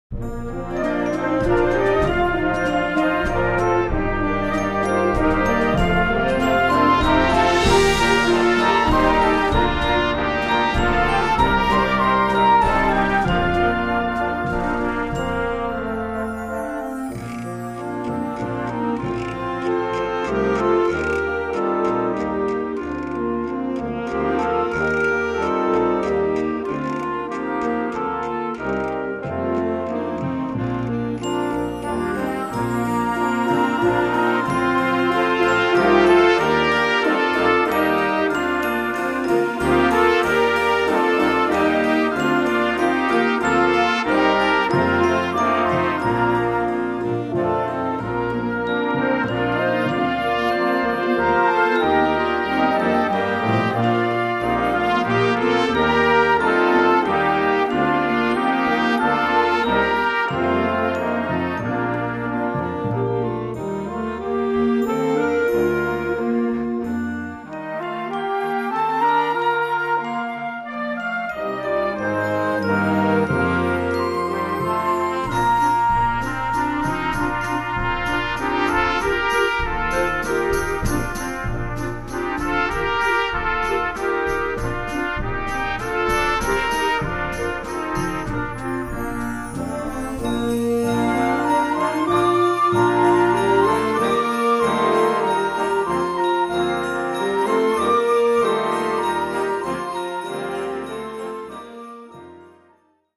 Recueil pour Harmonie/fanfare - Concert Band